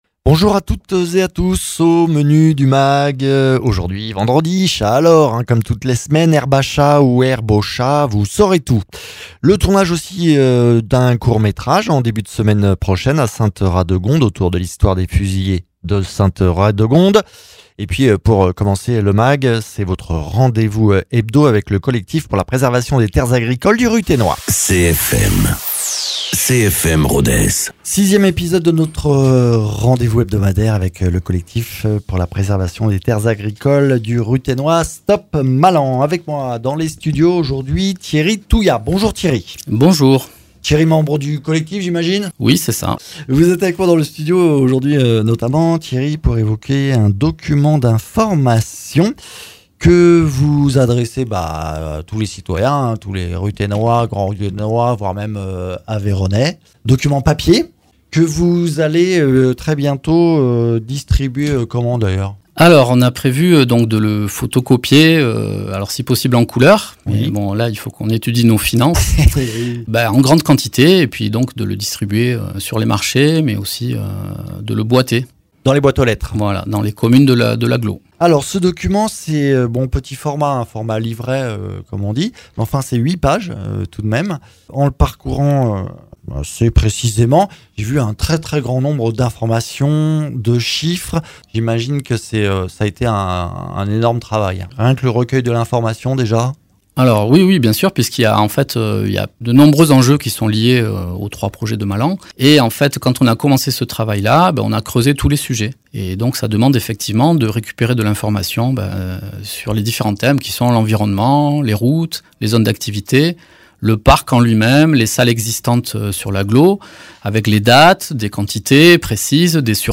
Mags